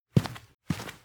player_walk.wav